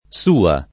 suo [S2o] agg.; f. sua [
S2a]; pl. m. suoi [SU0i], pl. f. sue [